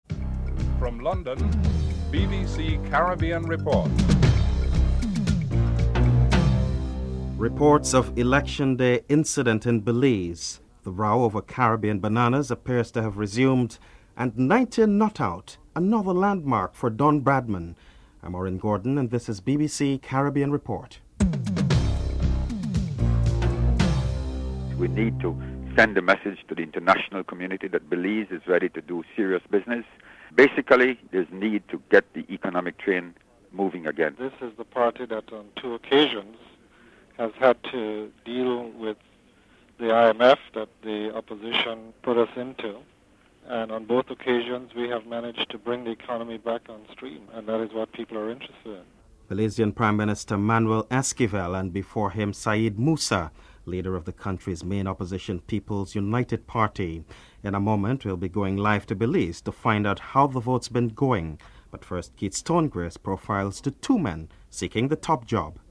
Former Australian Captain Greg Chappel and Sir Donald Bradman are interviewed.